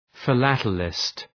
Προφορά
{fı’lætlıst}